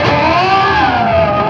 DIVEBOMB19-R.wav